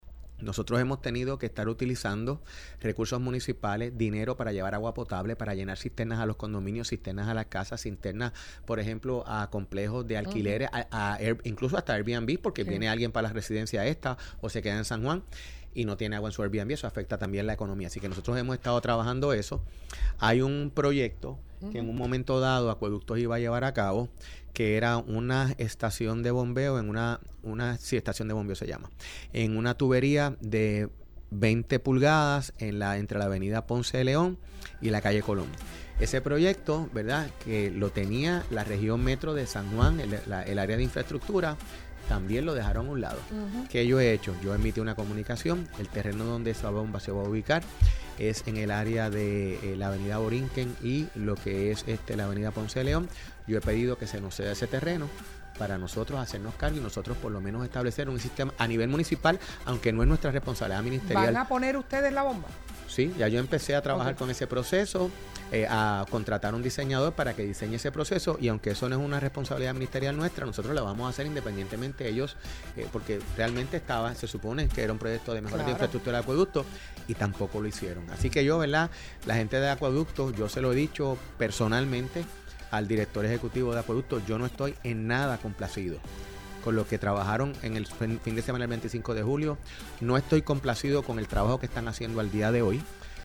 Hoy, en El Calentón, el alcalde de San Juan, Miguel Romero Lugo, demostró estar disgustado con los servicios ofrecidos por parte de la Autoridad de Acueductos y Alcantarillados (AAA) luego que el municipio enfrentara una crisis de de agua potable a finales de julio y principios de agosto.